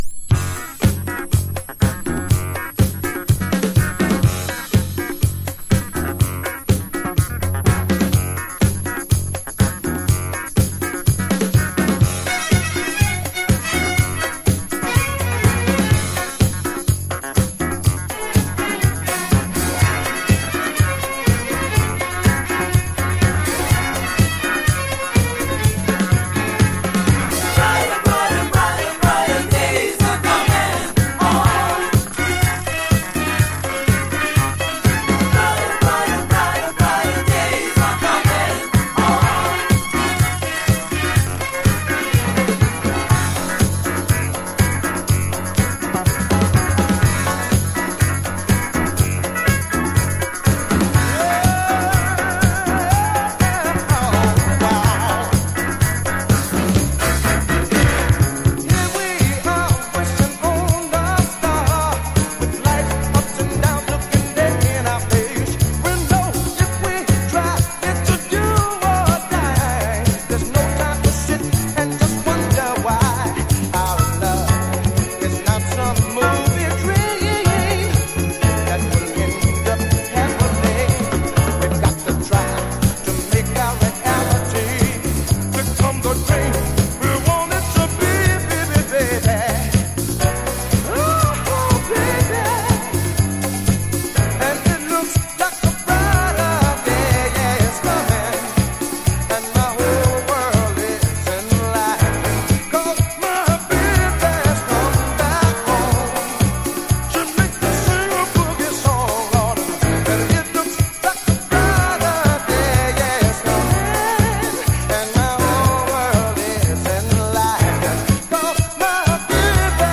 FUNK / DEEP FUNK# DISCO